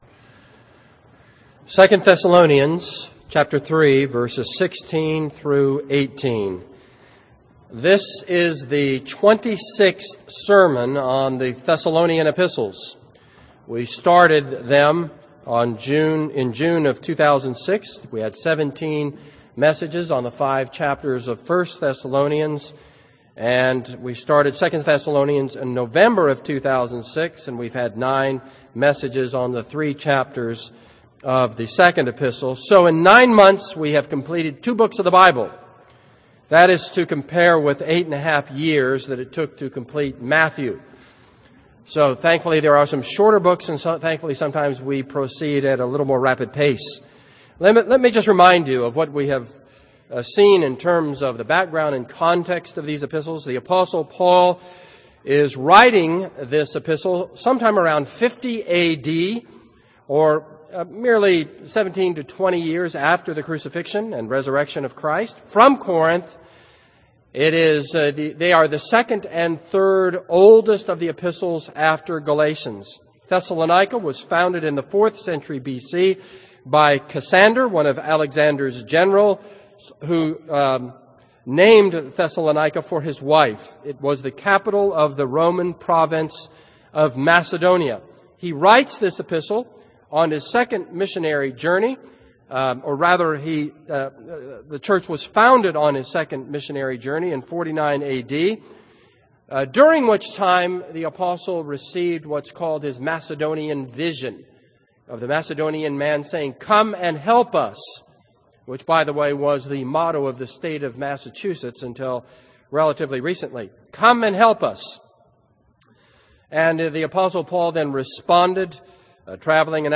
This is a sermon on 2 Thessalonians 3:16-18.